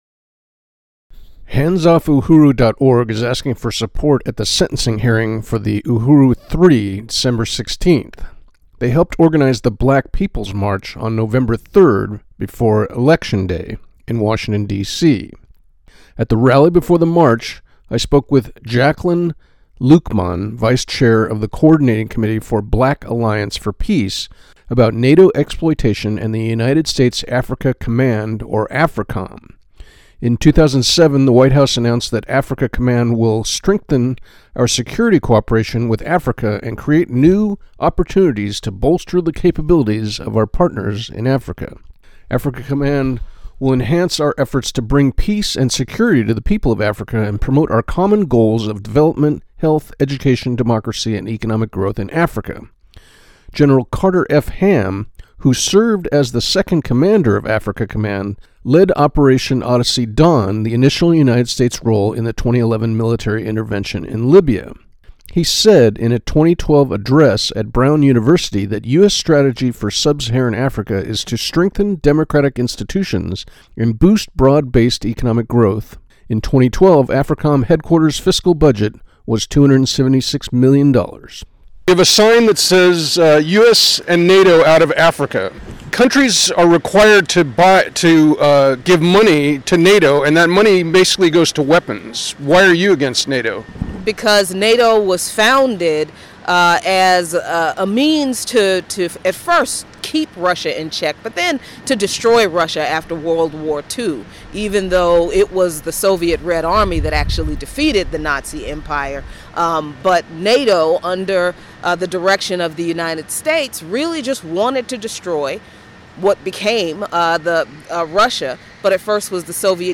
intrvwbapdc15m5s.mp3